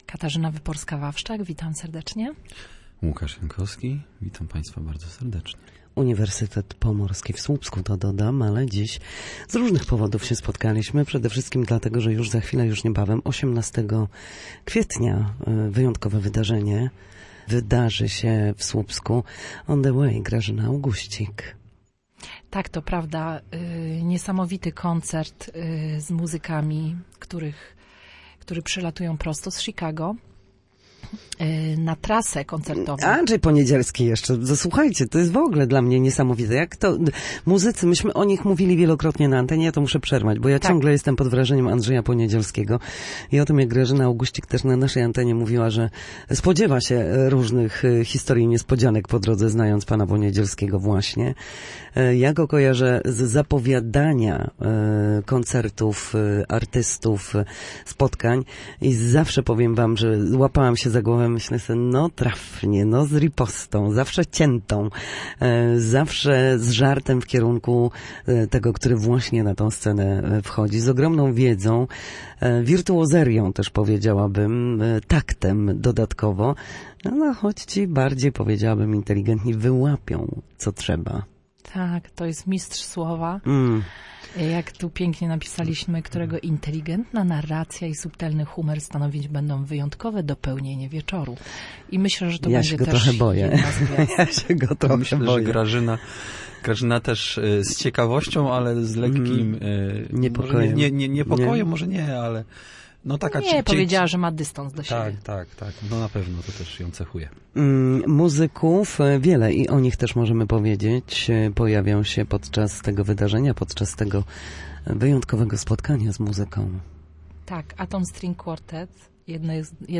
Studio Słupsk Radia Gdańsk